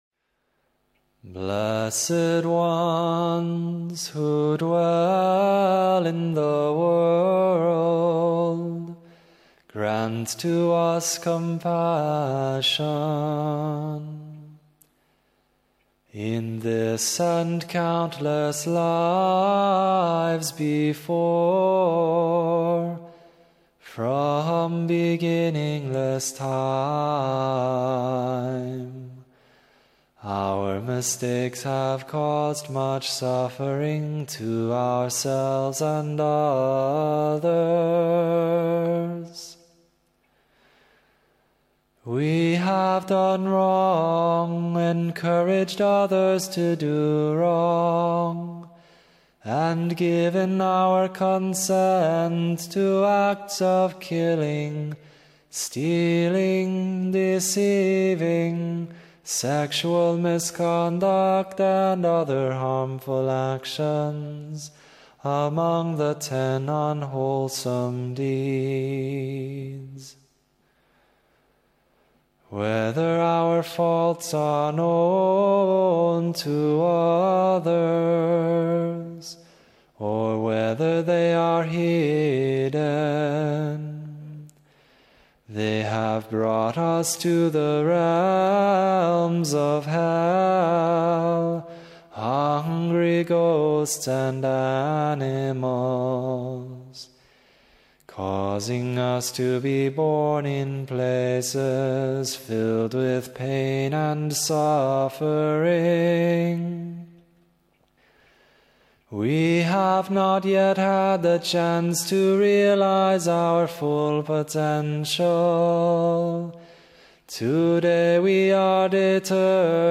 Joyfully Sharing the Merit- Chant.mp3